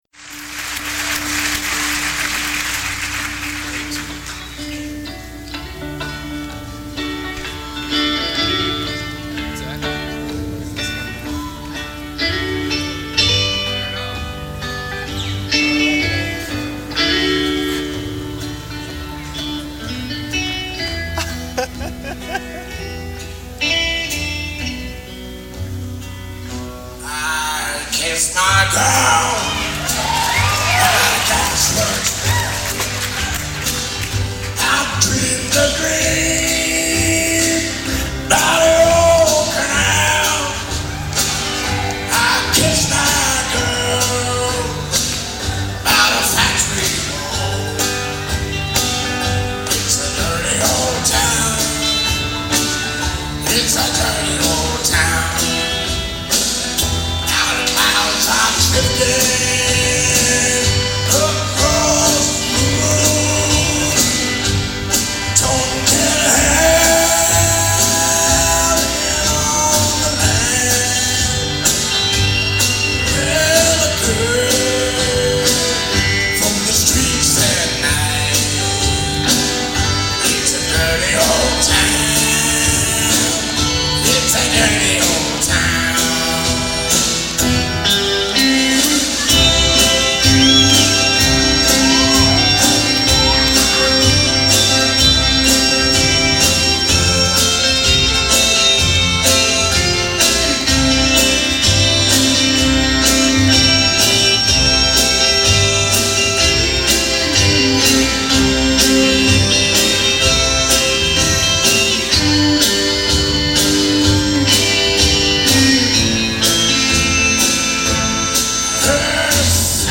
Raspy belt at full volume throughout.